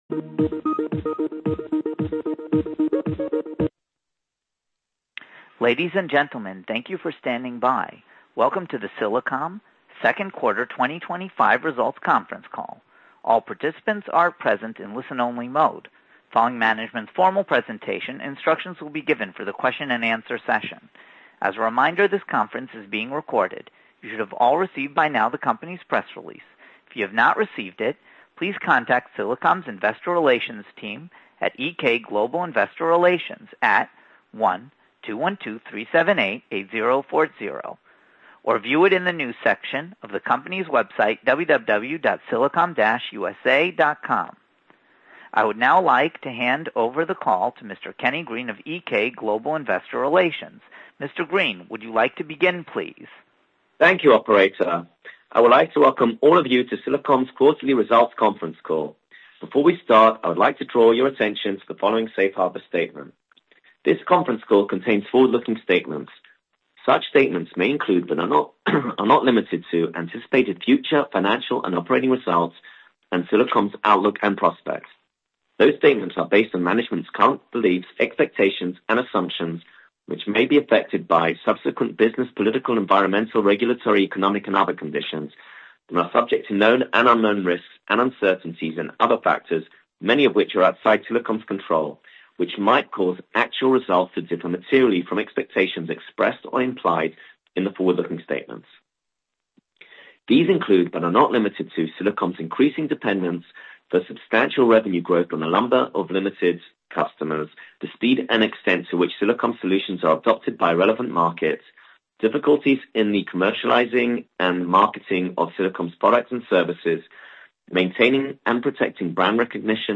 Earning Conference Call Q4 2023